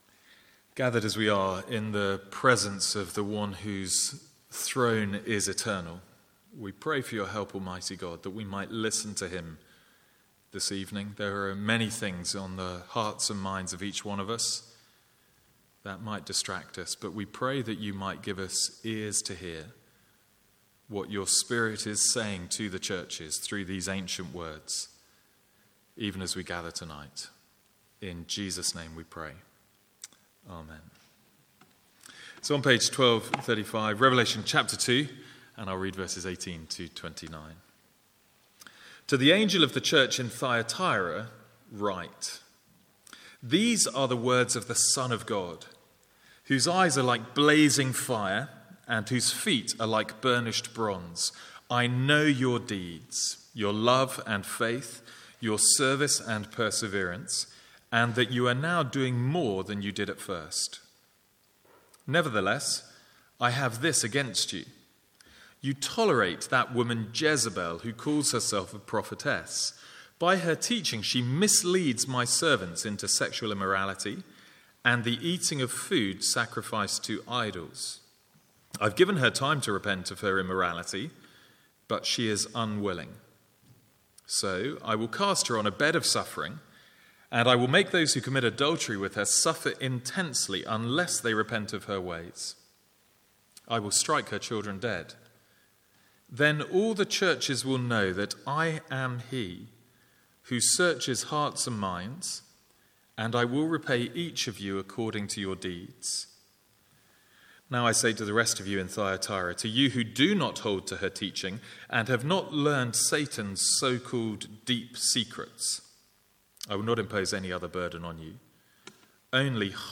Sermons | St Andrews Free Church
From the Sunday evening series in Revelation.